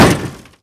metal_break.ogg